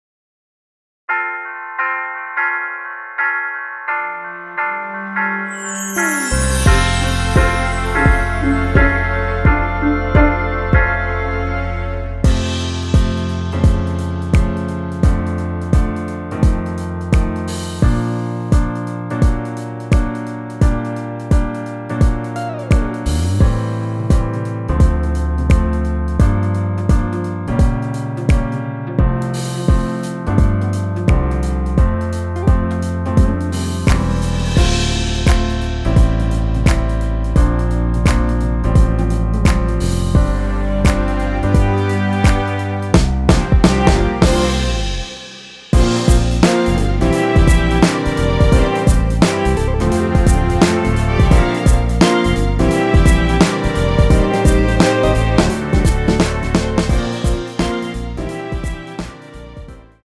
엔딩이 페이드 아웃이라서 노래하기 편하게 엔딩을 만들어 놓았으니 코러스 MR 미리듣기 확인하여주세요!
원키에서(-3)내린 MR입니다.
앞부분30초, 뒷부분30초씩 편집해서 올려 드리고 있습니다.